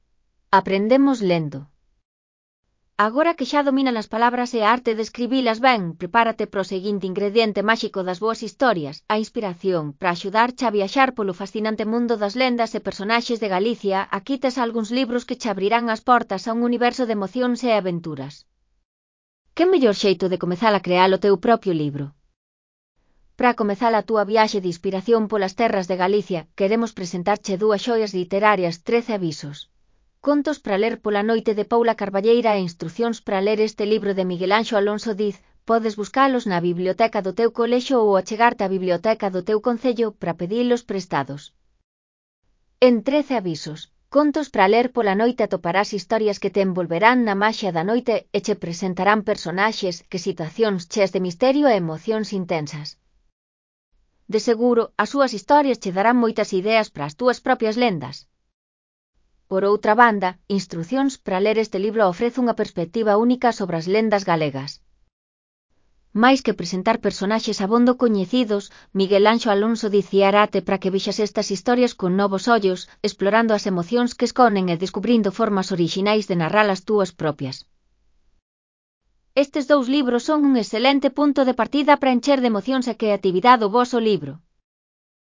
Lectura facilitada
Elaboración propia (proxecto cREAgal) con apoio de IA voz sintética xerada co modelo Celtia.